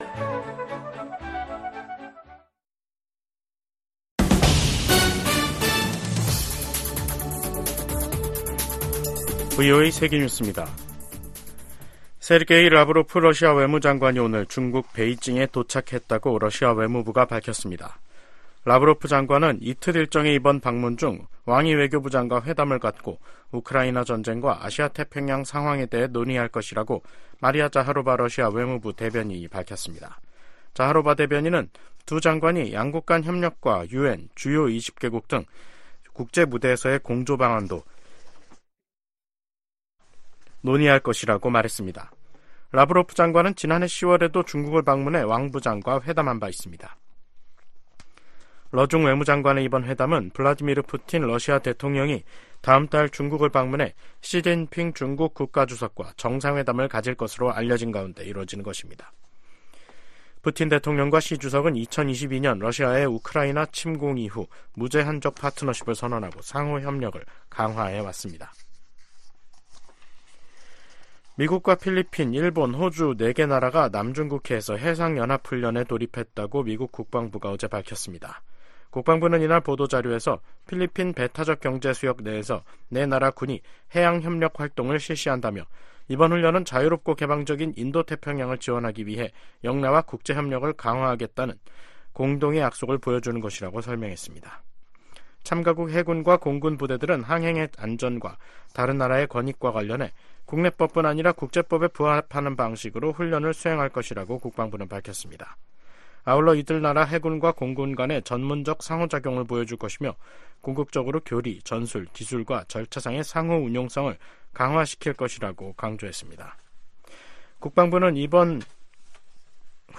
VOA 한국어 간판 뉴스 프로그램 '뉴스 투데이', 2024년 4월 8일 2부 방송입니다. 전천후 영상 정보 수집이 가능한 한국의 군사정찰위성 2호기가 8일 발사돼 궤도에 안착했습니다. 북한 김일성 주석을 ‘가짜’로 판단하는 1950년대 미국 정부 기밀 문건이 공개됐습니다. 미 국무부는 러시아가 한국의 대러 독자제재에 반발,주러 한국대사를 불러 항의한 것과 관련해 한국의 제재 조치를 환영한다는 입장을 밝혔습니다.